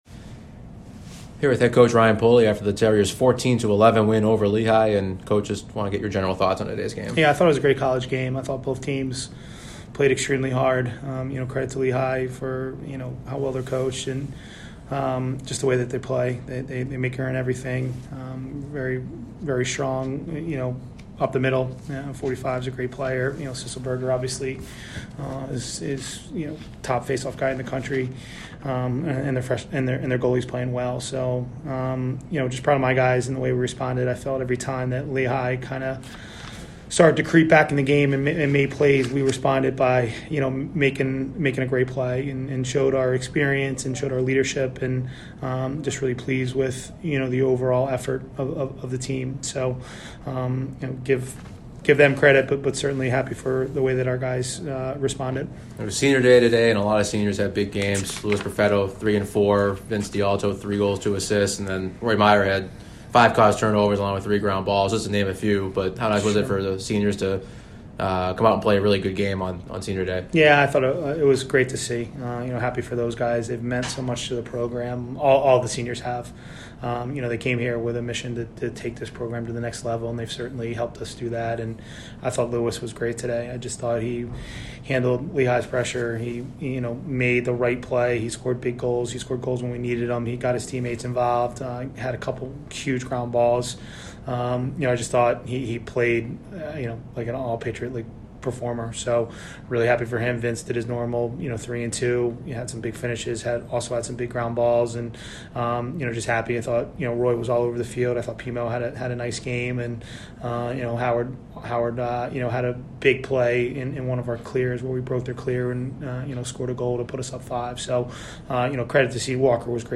Lehigh Postgame Interview